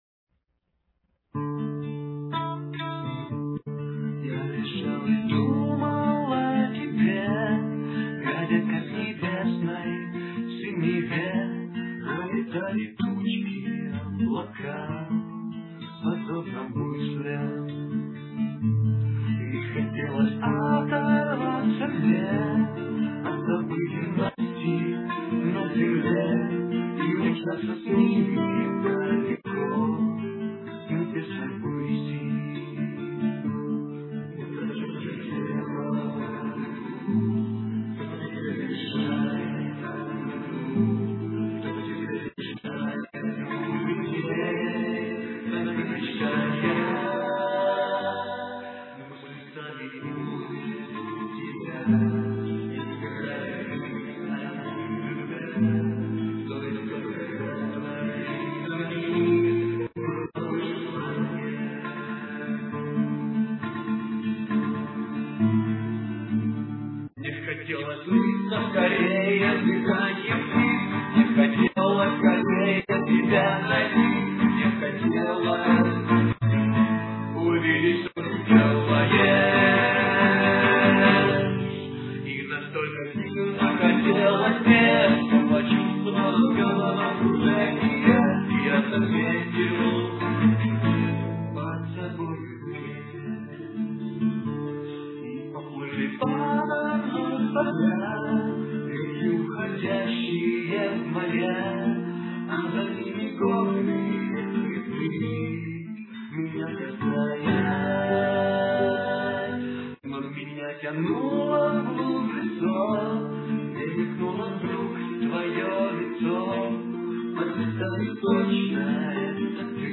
Verses (Dm-B-Gm-A-Dm) -- mute picking. Refrain (Gm---B-A-D-G) -- hard beat.